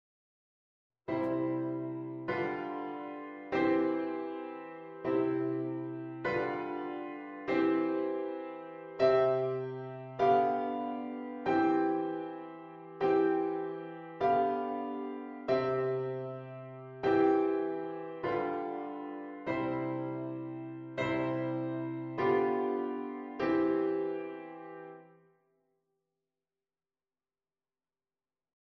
V4/3 als doorgangsakkoord tussen I en I6 (en: combinatie met 'sopraanfiguurtjes')
a. in majeur: